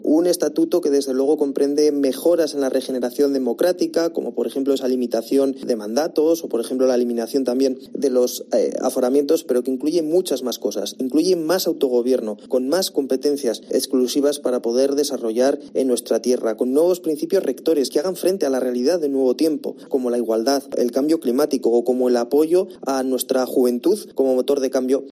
Raúl Díaz, portavoz parlamentario del PSOE